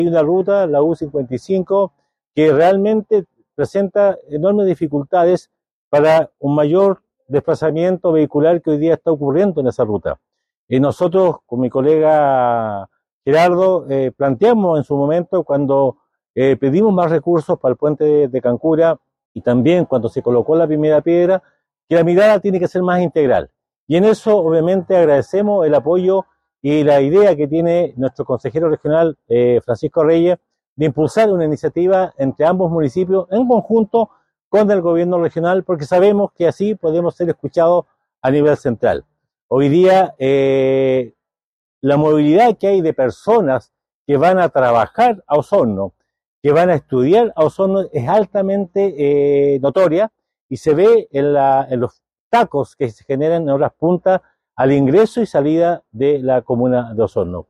En la última sesión plenaria del Consejo Regional de Los Lagos, realizada en la comuna de Puerto Octay, se discutió la posibilidad de mejorar la ruta U-55, que conecta las comunas de Puerto Octay y Osorno.
El alcalde de Osorno, Emeterio Carrillo, subrayó la relevancia de este proyecto para el desarrollo de la región, pues actualmente la ruta U-55, presenta enormes dificultades para el desplazamiento vehicular seguro, con una mirada integral  que responsa a las necesidades de ambas comunas.